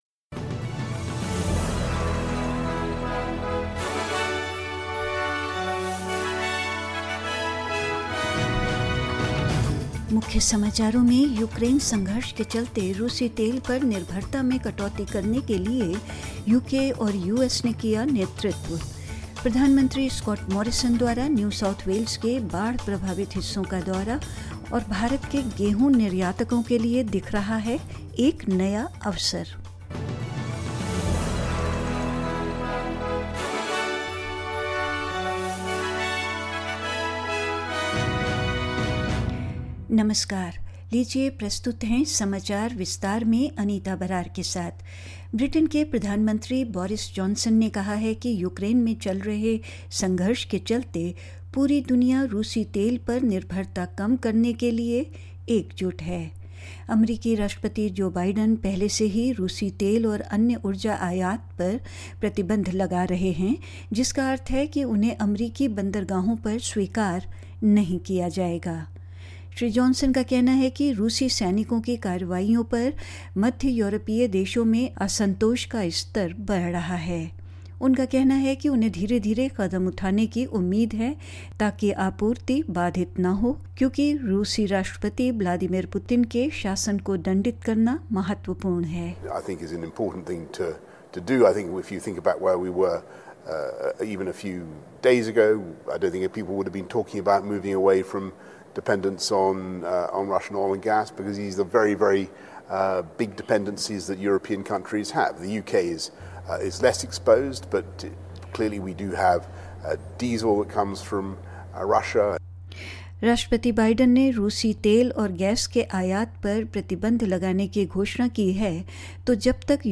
In this latest SBS Hindi bulletin: The UK and US lead the charge to cut dependence on Russian oil in response to the Ukraine conflict; Prime Minister Scott Morrison is touring flood affected parts of New South Wales; Western Australia offers cricketing great Rod Marsh a state funeral to honour his life and more news.